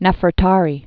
(nĕfər-tärē) 14th-13th century BC.